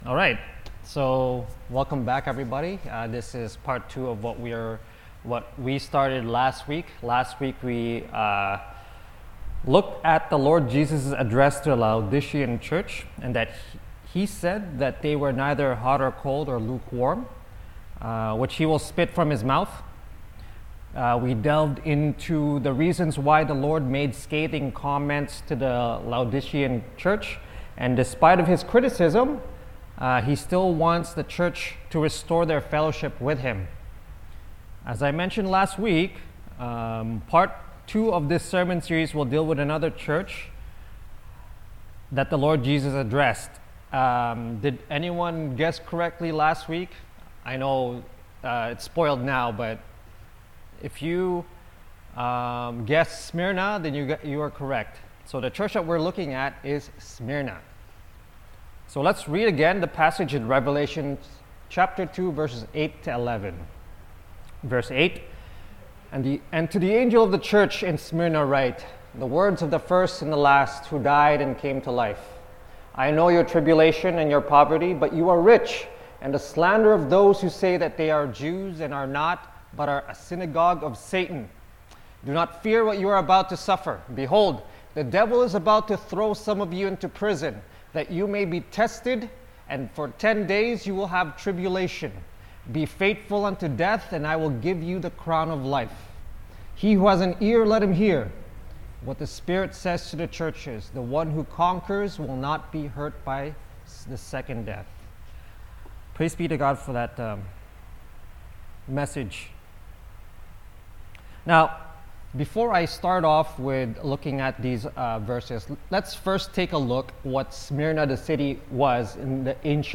We delved into the reasons why the Lord made scathing comments to the Laodicean church and despite of His criticism, He still wants the church to restore their fellowship with Him. As I mentioned last week, part two of this sermon series will deal with another church that the Lord Jesus addressed, and this church is the church of Smyrna.